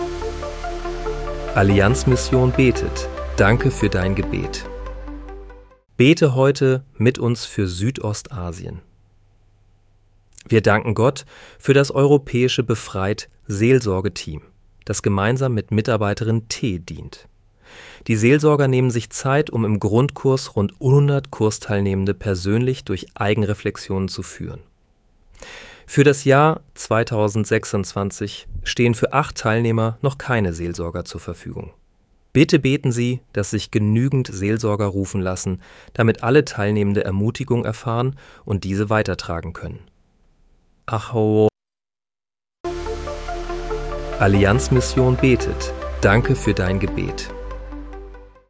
Bete am 01. Januar 2026 mit uns für Südostasien. (KI-generiert mit